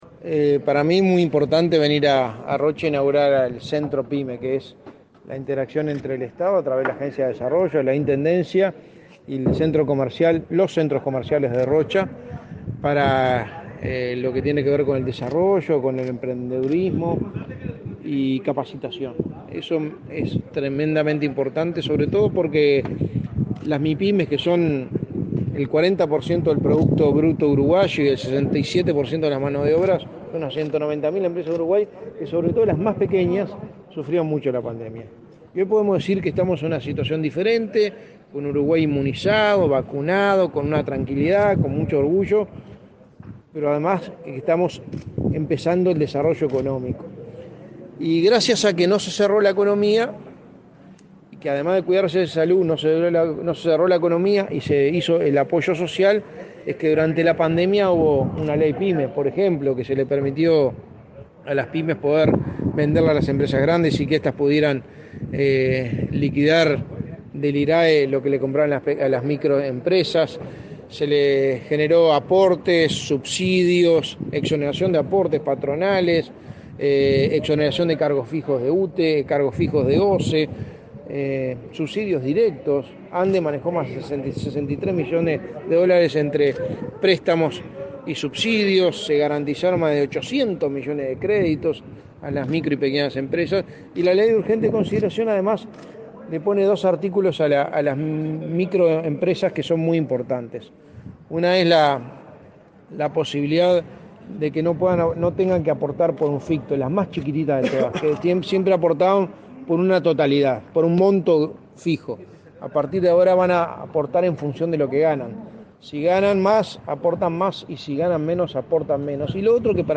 Declaraciones de prensa del secretario de la Presidencia, Álvaro Delgado
Tras el acto por la inauguración del Centro Pyme en Rocha, este 16 de noviembre, el secretario de la Presidencia efectuó declaraciones a la prensa.
delgado prensa.mp3